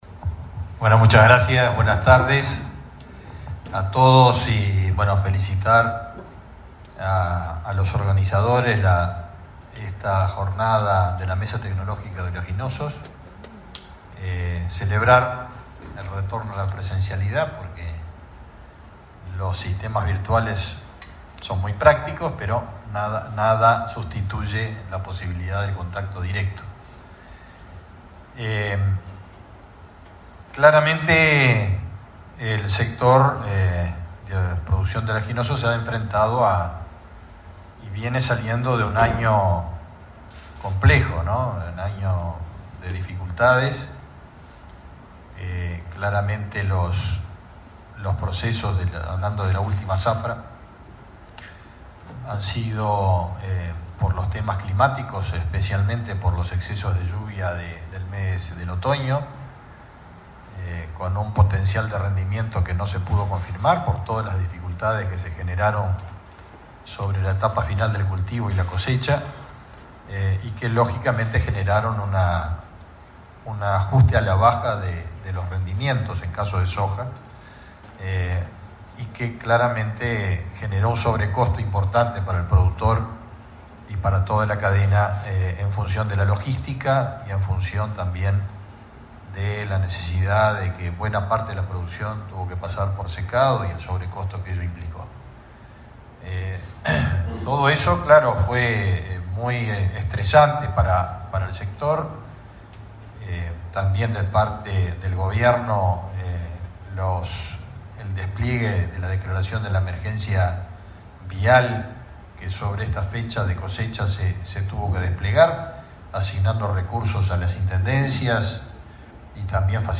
El ministro de Ganadería, Fernando Mattos, hizo uso de la palabra en la apertura del 13.° encuentro nacional de la Mesa Tecnológica de Oleaginosos
El evento se realizó este miércoles 9 en el Laboratorio Tecnológico del Uruguay.